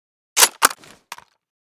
mosin_close.ogg